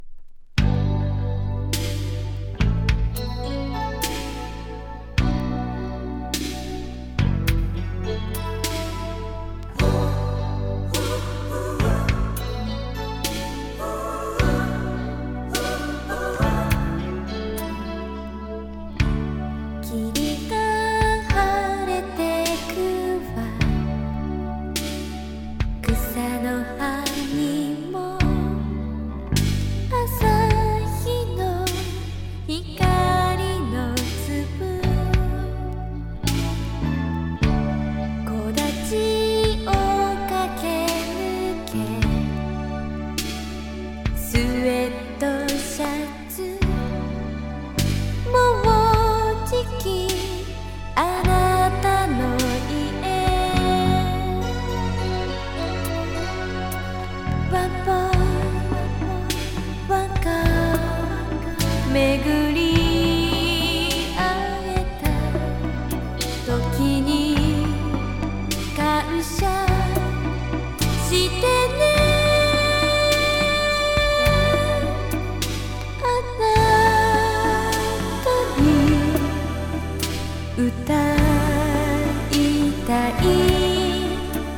ドラムマシーンの音色がたまらないモダンソウル・スローバラード